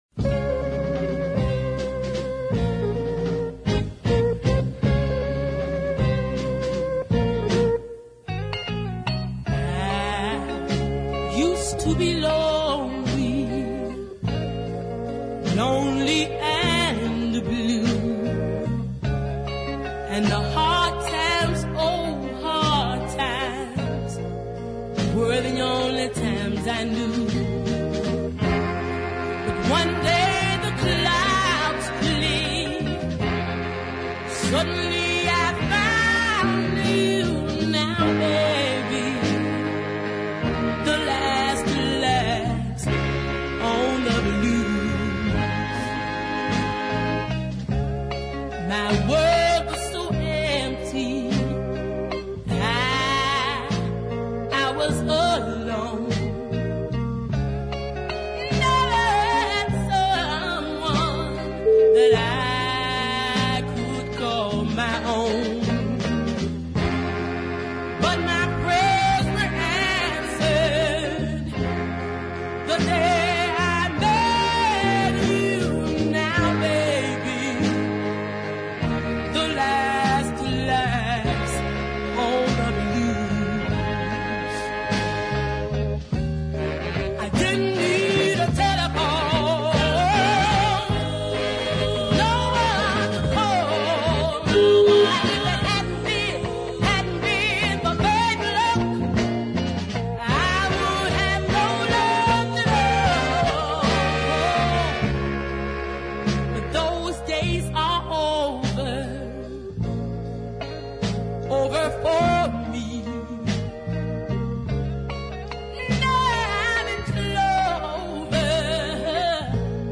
is a deep ballad of no little worth